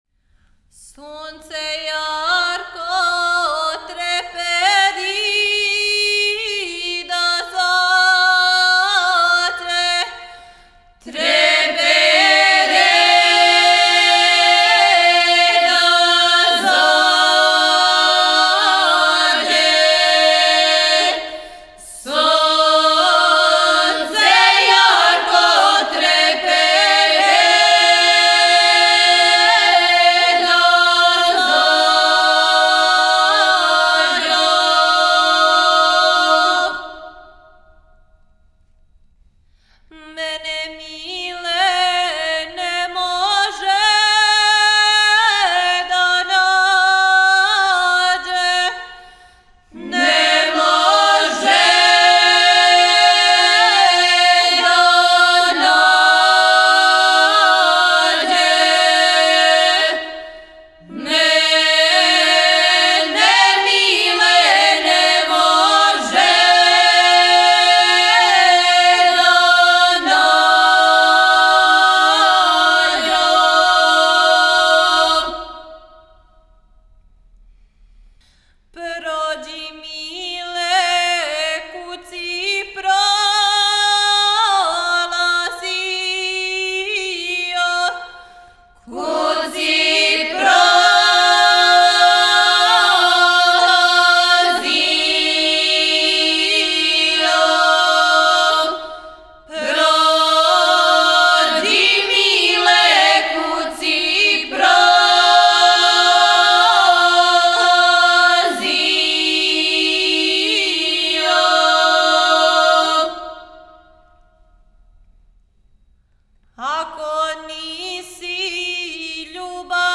Порекло песме: Западна Србија Начин певања: На бас. Напомена: Љубавна песма забележена у селу Лоци